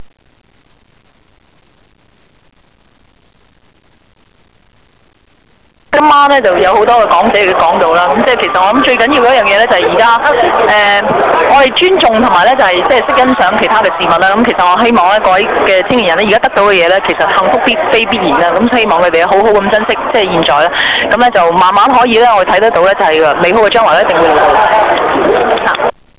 由青少年暑期活動員會及傑出青年協會合辦的「飛躍人生」講座經已於十一月二十日完滿結束。
當晚香港大球場體育大樓的賽馬會演講廳座無虛設，參加者多數是青少年。